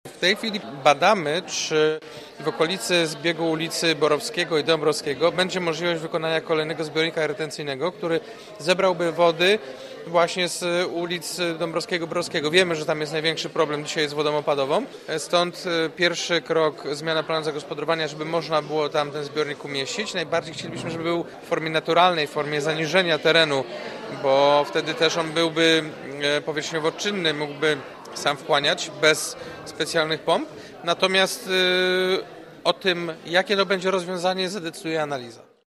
Mówi prezydent Jacek Wójcicki: